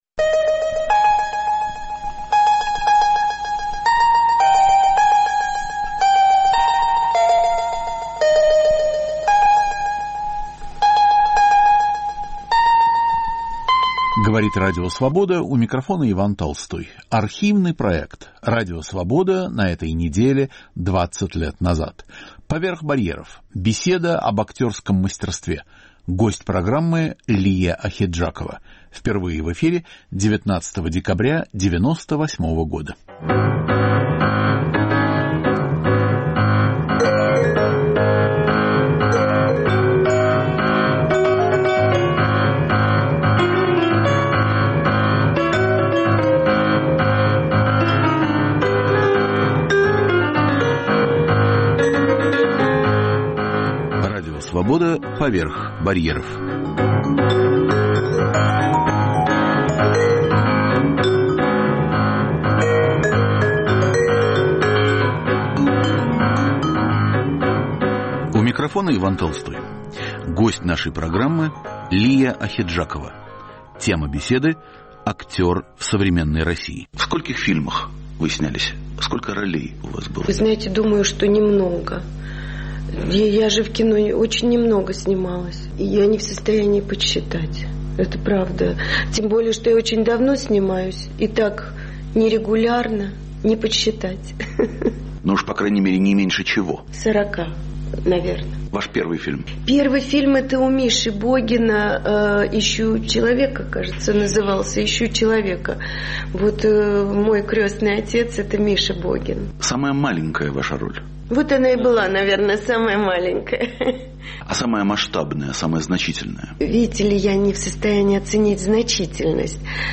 Радио Свобода на этой неделе 20 лет назад. Наш гость - Лия Ахеджакова
Архивный проект.